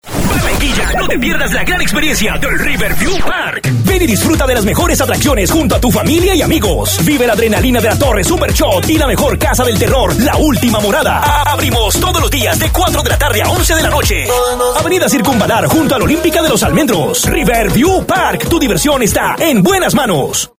Voz comercial para radio